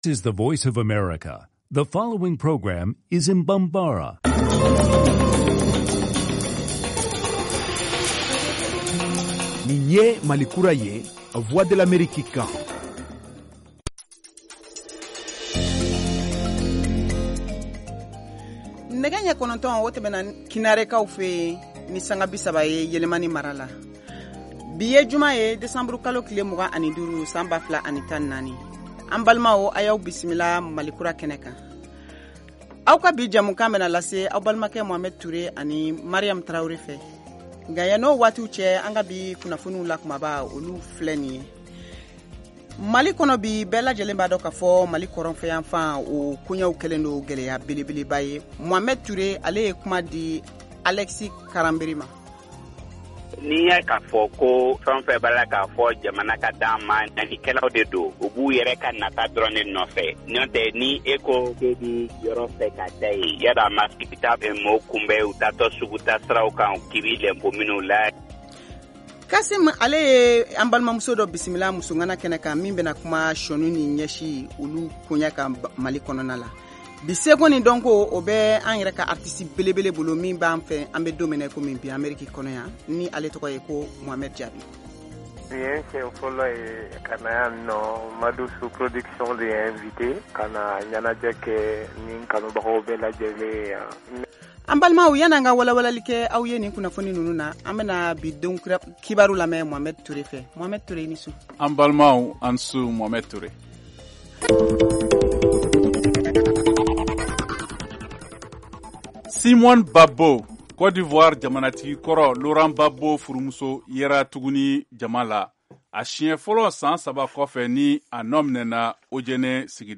en direct de Washington, DC, aux USA.